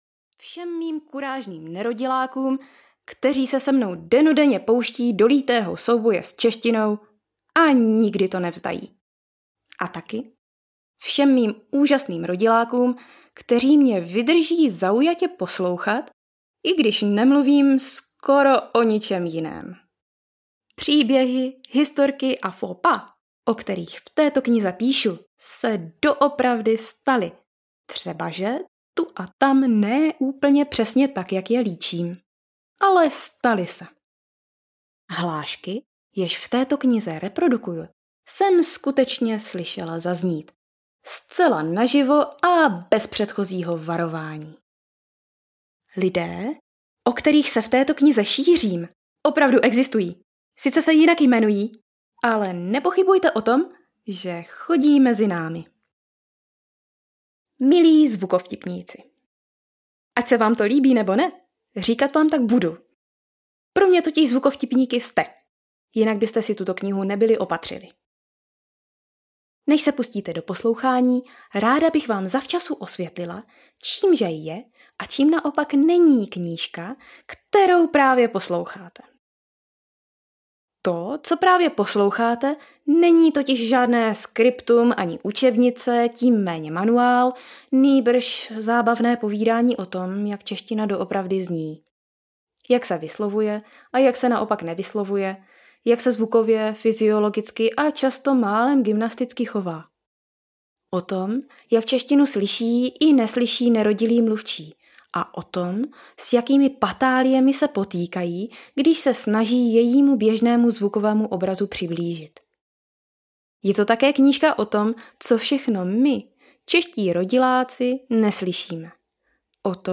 Proč to nemusíš slyšet audiokniha
Ukázka z knihy